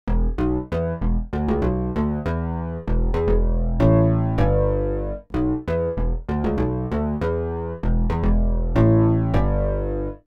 Sachant que les sons que tu nous as fait écouter sont traité avec des effets style reverbe etc.
Mais il s'agit bel et bien de sons typiquement FM.
Rien que des sons FM très classiques et tout à fait reproductibles sur un DX7.